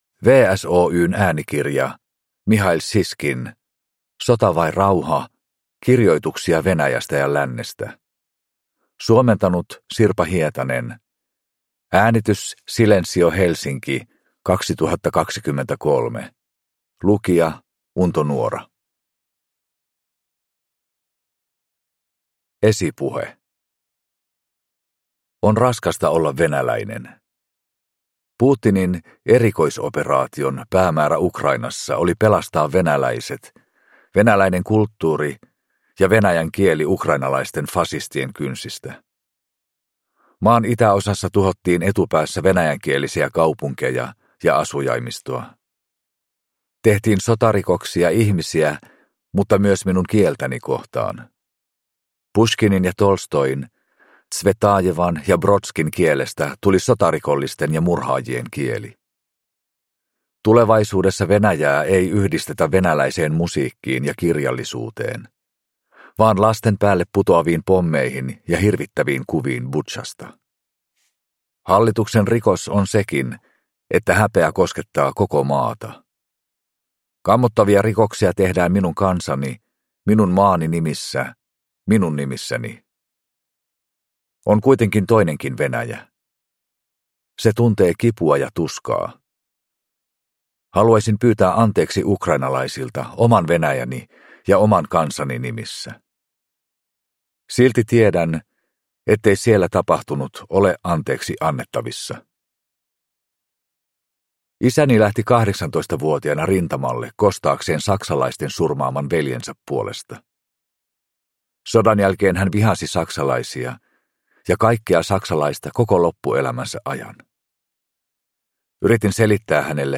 Sota vai rauha – Ljudbok – Laddas ner